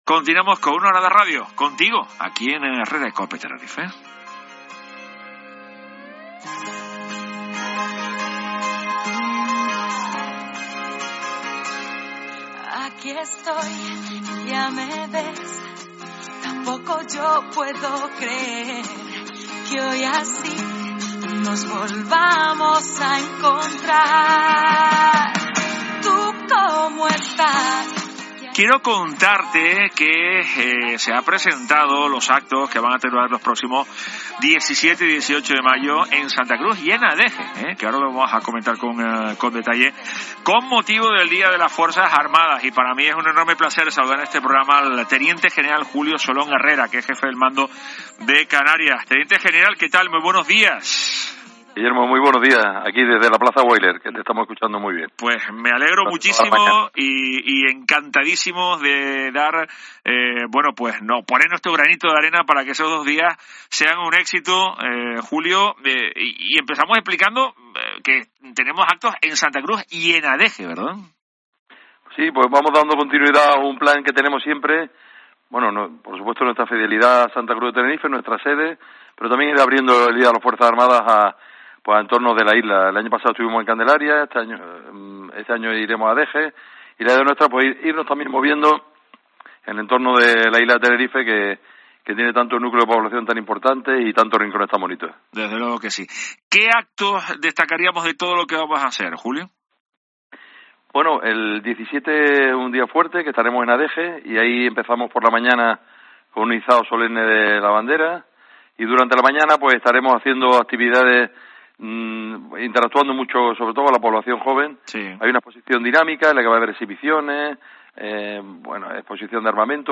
Entrevista al Teniente General Julio Salom Herrera, Jefe del Mando de Canarias